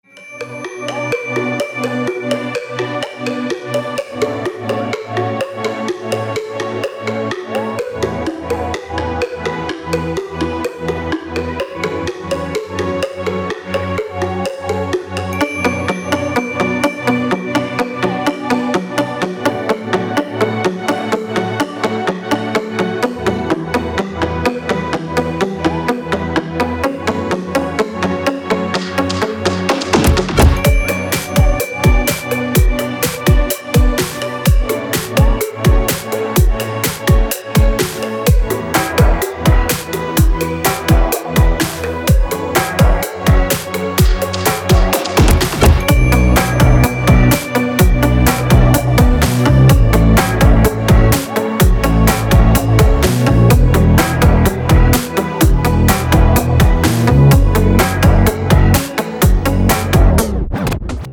• Категория: Красивые мелодии и рингтоны